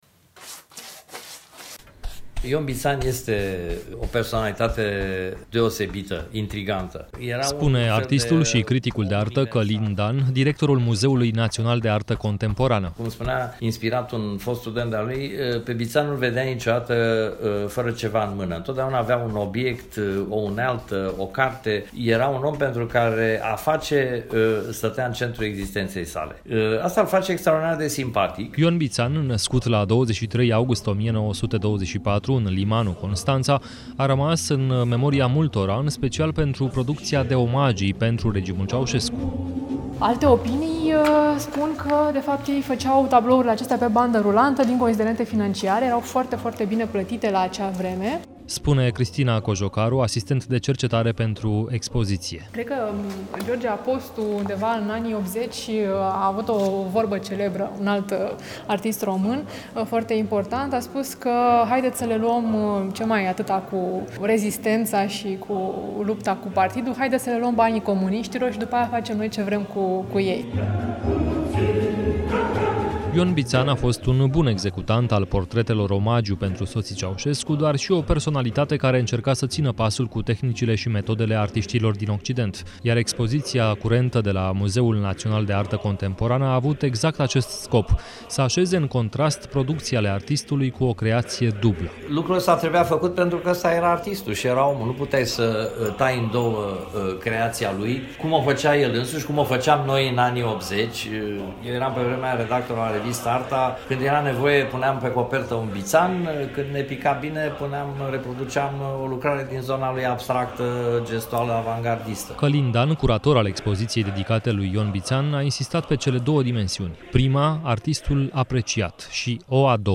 Reportaj difuzat în emisiunea „Lumea Europa FM”, duminică, 20 mai 2018, imediat după „Noaptea muzeelor”